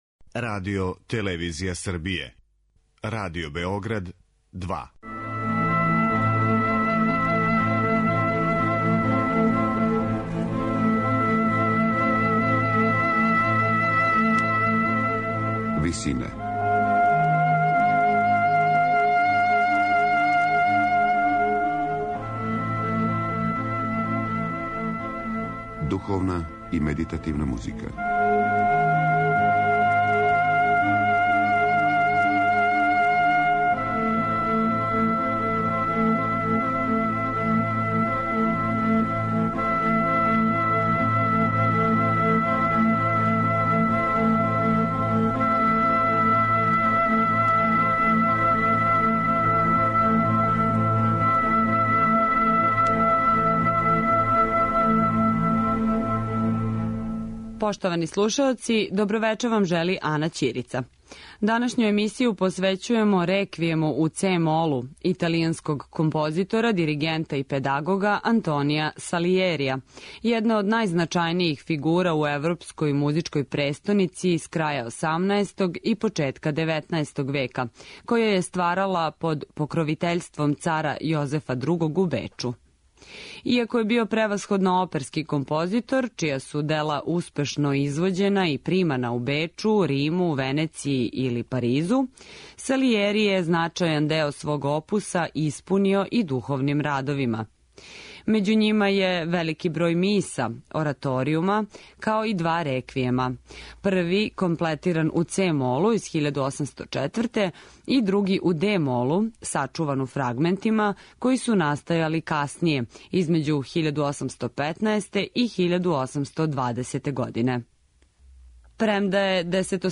Антонио Салијери: Реквијем у це-молу
медитативне и духовне композиције